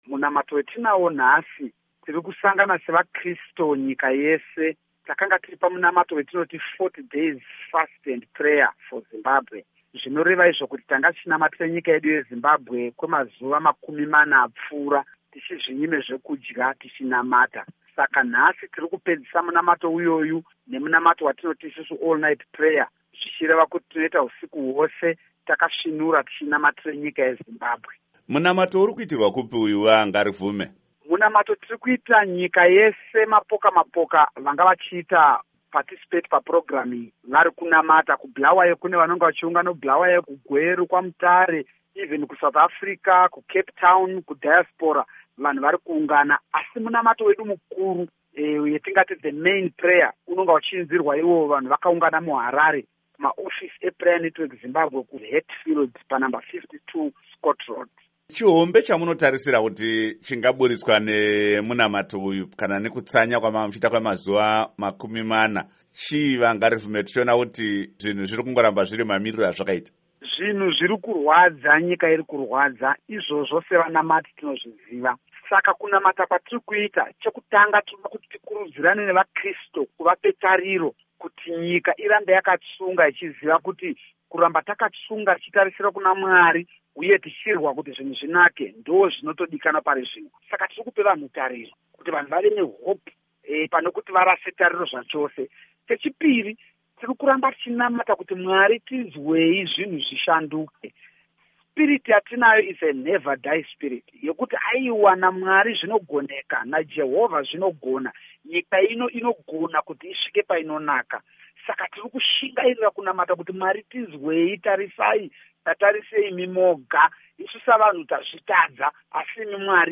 Hurukuro naVaJacob Ngarivhume